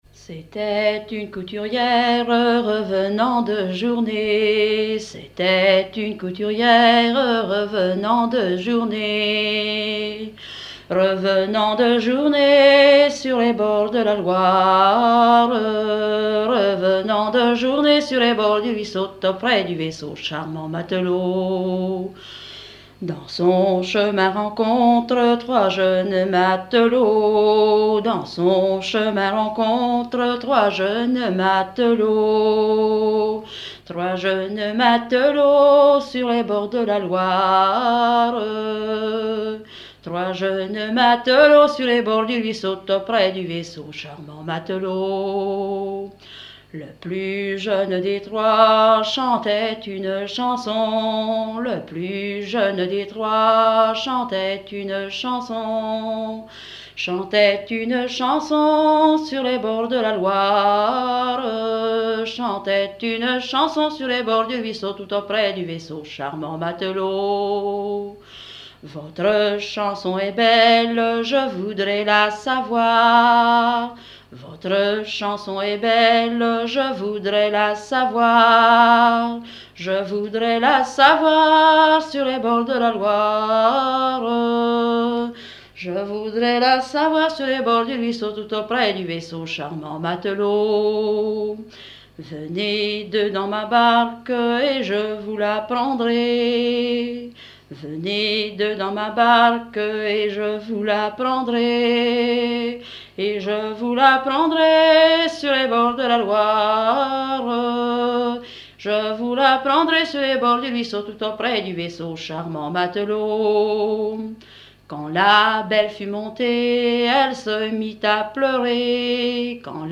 danse : ronde
Pièce musicale inédite